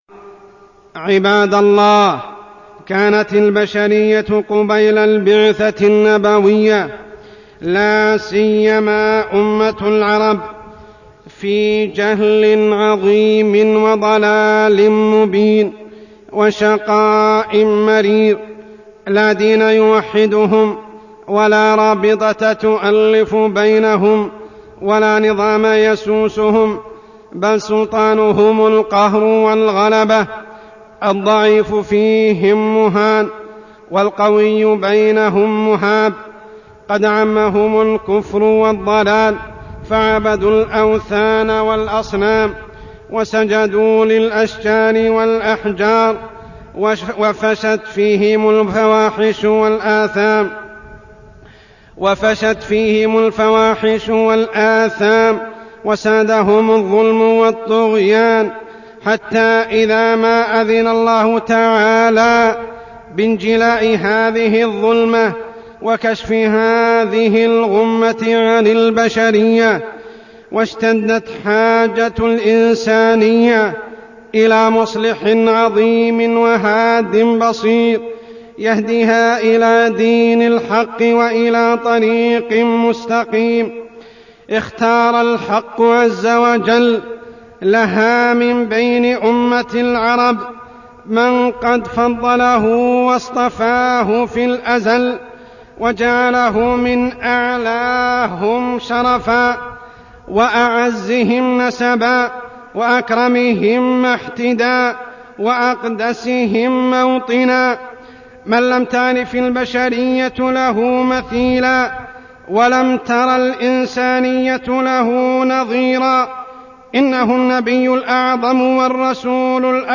خطبة الجمعة 7-3-1418هـ > خطب الحرم المكي عام 1418 🕋 > خطب الحرم المكي 🕋 > المزيد - تلاوات الحرمين